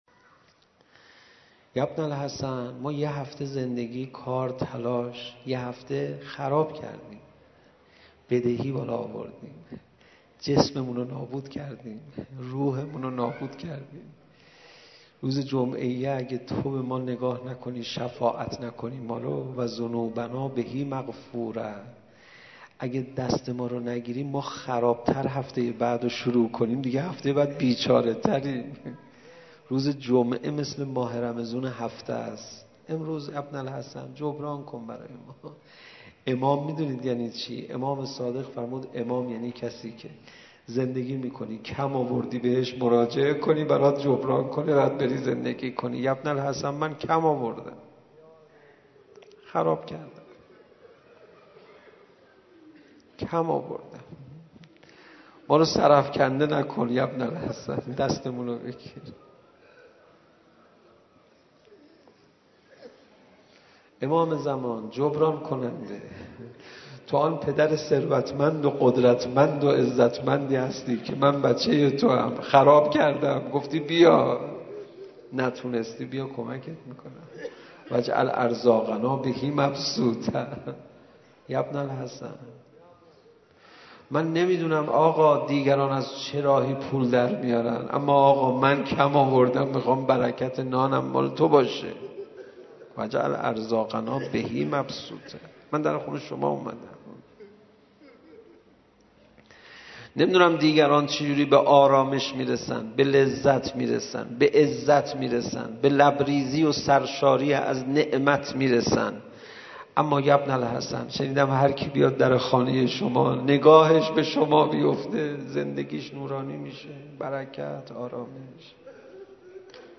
مناجات با امام زمان عج